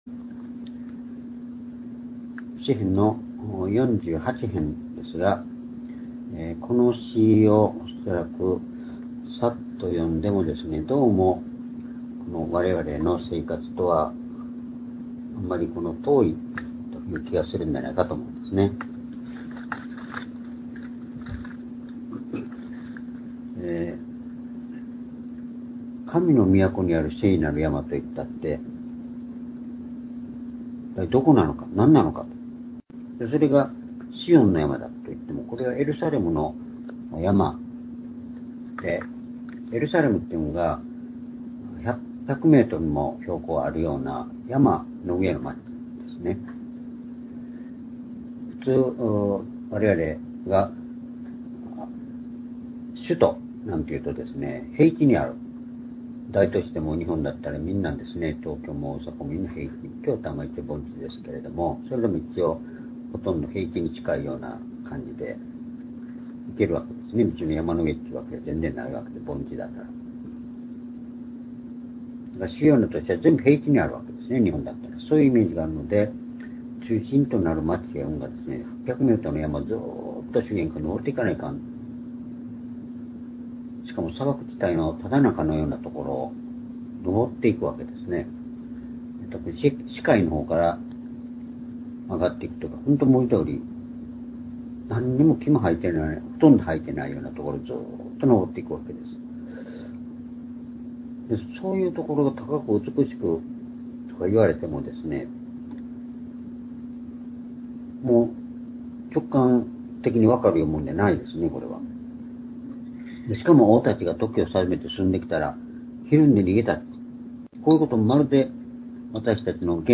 （主日・夕拝）礼拝日時 2025年3月18日(夕拝) 聖書講話箇所 「神の国の永遠」 詩編48編2～12節 ※視聴できない場合は をクリックしてください。